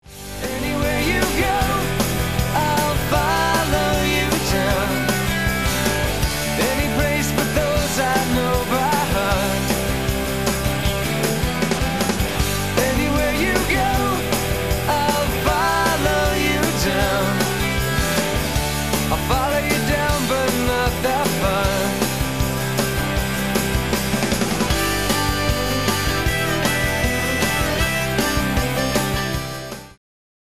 Midi Music